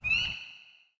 frog2.wav